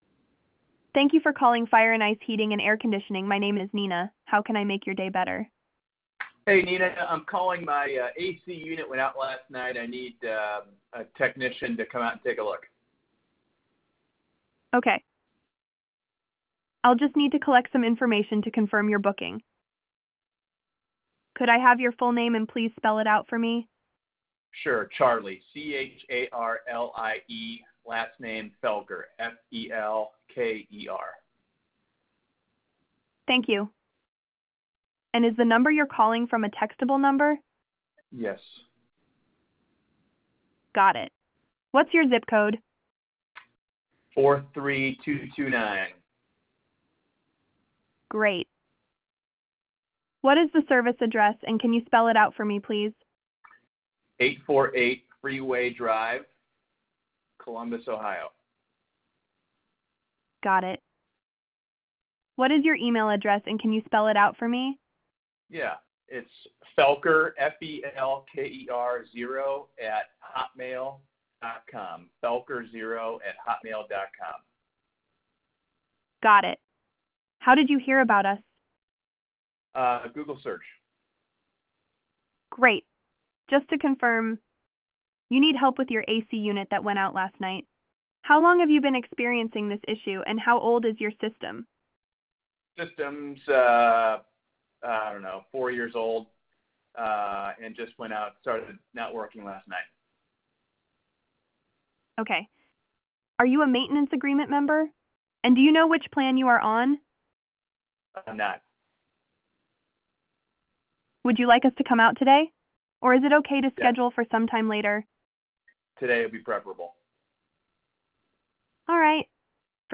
Fire-Ice-Heating-AI-Voice-Sample.wav